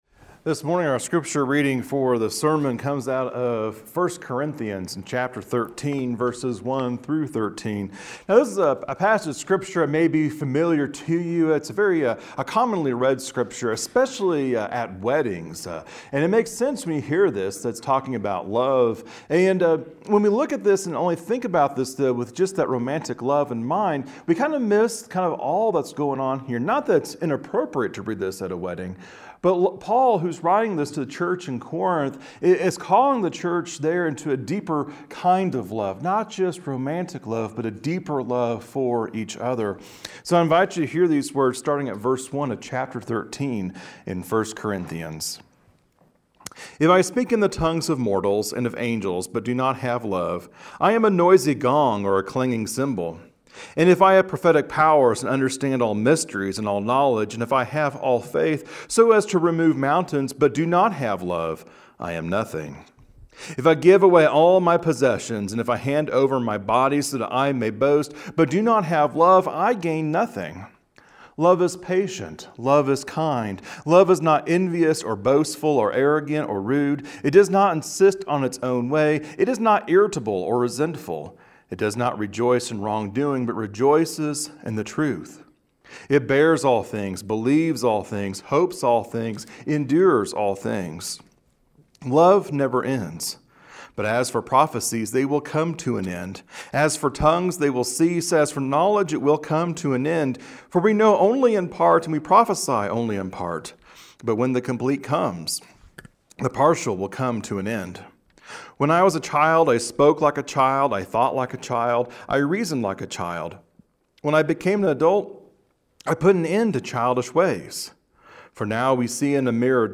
Sunday-Sermon-February-3.mp3